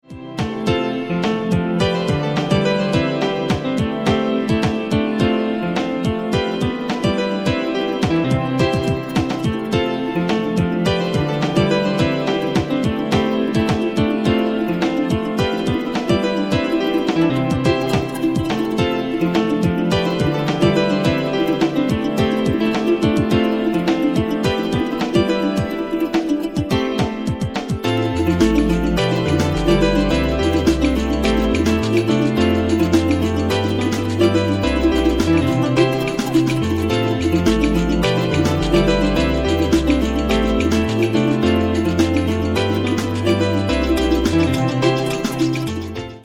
electronica idm drum'n'bass neojazz